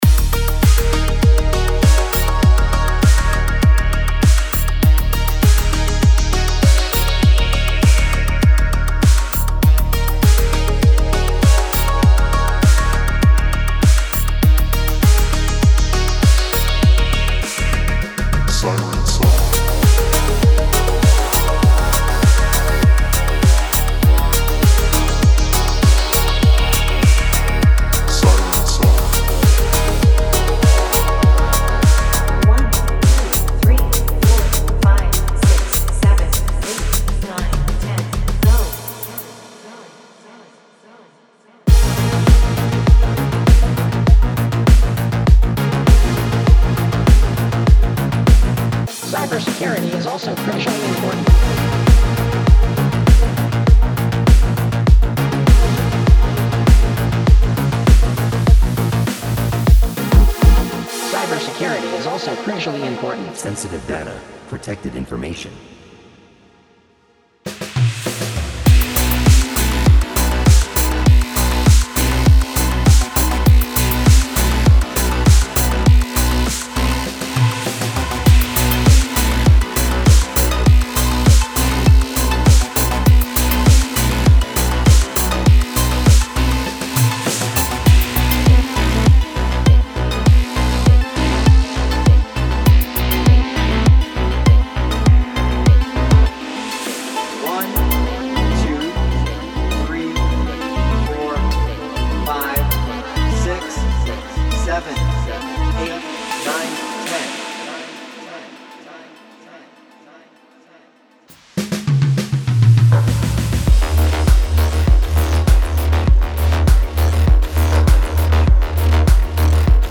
Type: Serum Midi Samples Genre: Synthwave / Retrowave